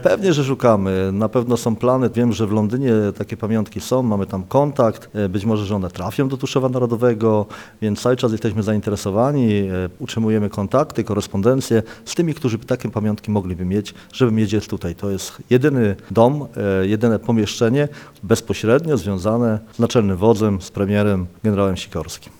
Centrum Pamięci generała Władysława Sikorskiego w Tuszowie Narodowym może wkrótce wzbogacić się o nowe pamiątki związane z generałem. Jak mówi wójt gminy Tuszów Narodowy Andrzej Głaz, cały czas prowadzone są rozmowy z właścicielami tych rzeczy, aby mogły one trafić do naszego centrum pamięci o generale: